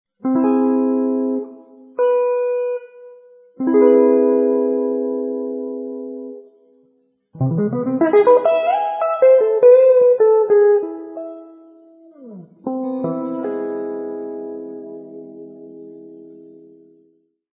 Se alla stessa triade aumentata (C,E,G#) aggiungiamo una terza minore, otterremo C,E,G#,B, definibile come Cmaj7#5 oppure Cmaj7aug [